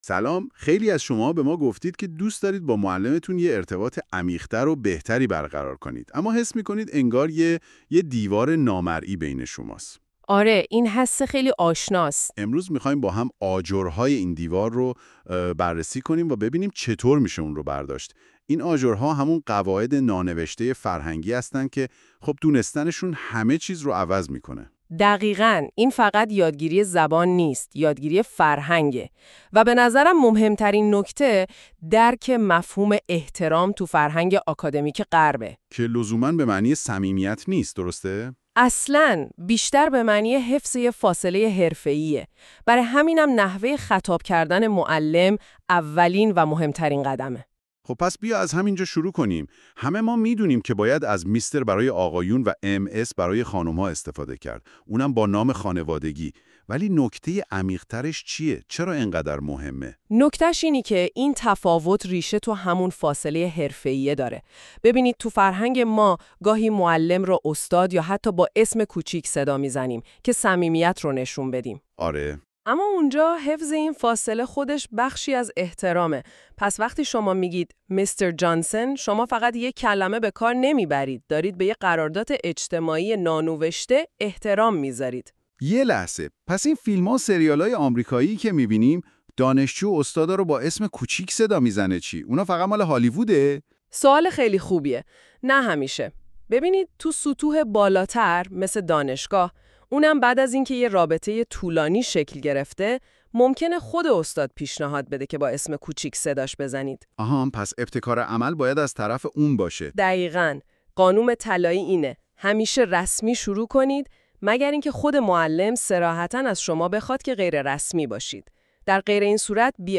english-conversation-about-a-teacher.mp3